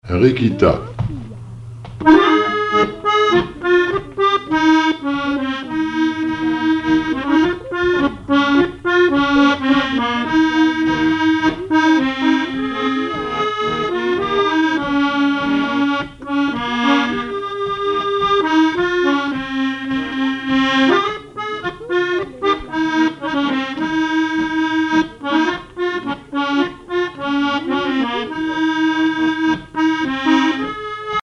accordéon(s), accordéoniste
danse : java
Pièce musicale inédite